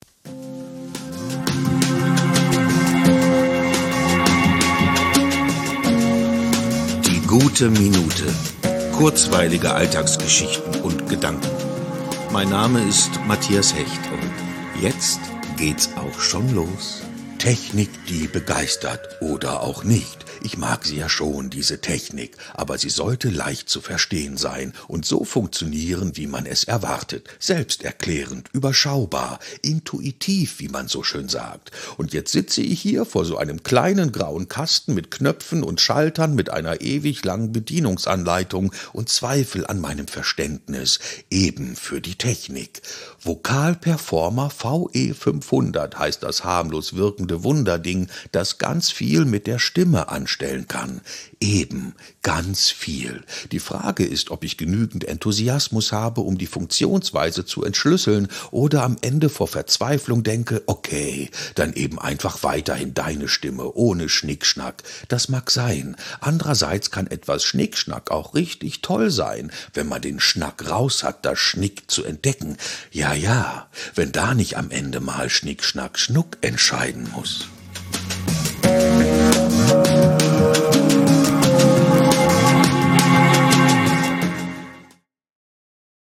"Ein Ultrakurzpodcast über die Schönheit des Alltäglichen(...)